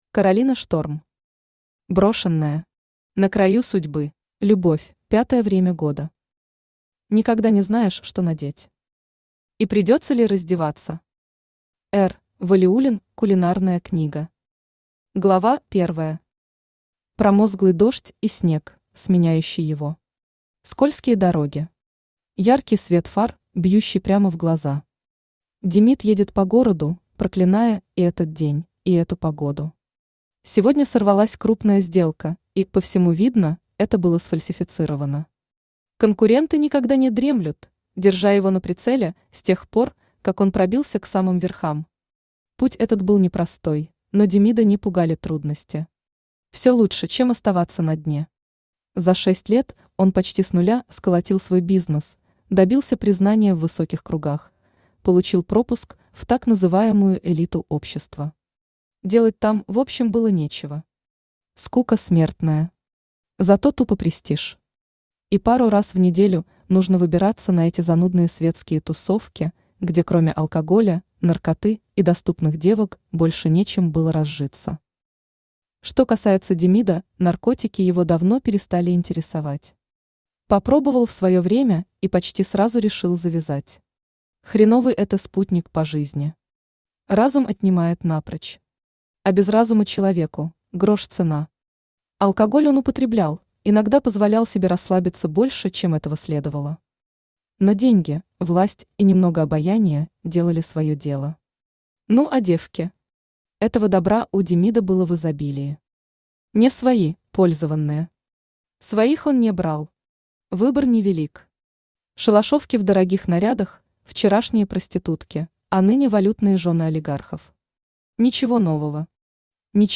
Aудиокнига Брошенная. На краю судьбы Автор Каролина Шторм Читает аудиокнигу Авточтец ЛитРес.